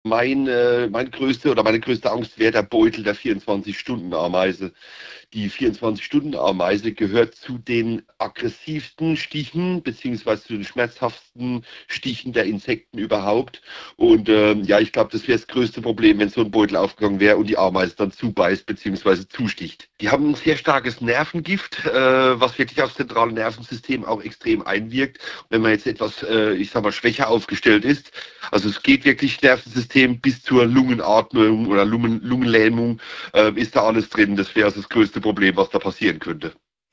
Nachrichten Schmuggelversuch in Lima: Das wäre die giftigste Tierart